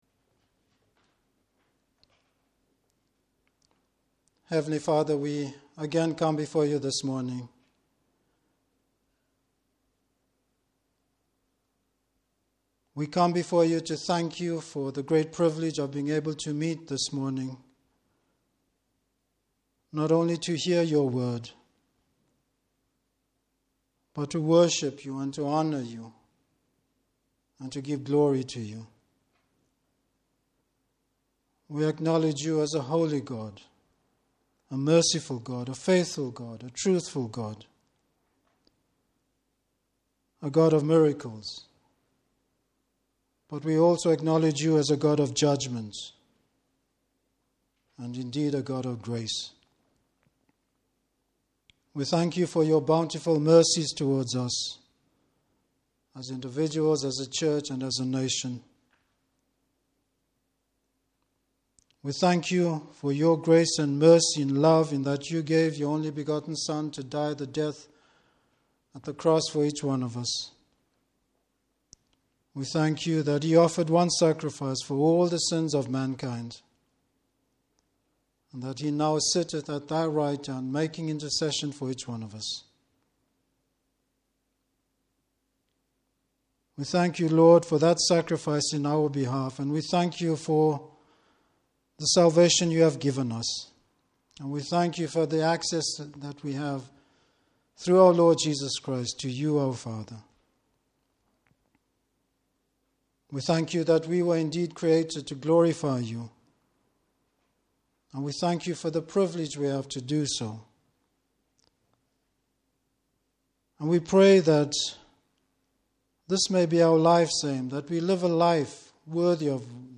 Service Type: Morning Service Bible Text: Job 29-31.